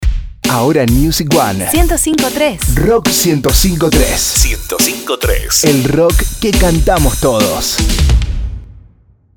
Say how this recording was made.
Although this sounds very distorted sound.